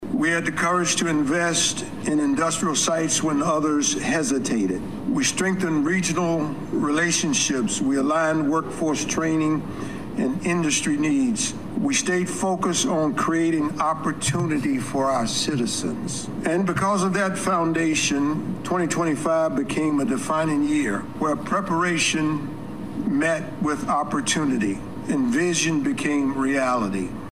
Pittsylvania County Board of Supervisors Chairman Robert Tucker Delivered the State of the County address last night. Tucker opened the address by celebrating a successful 2025 for Pittsylvania County.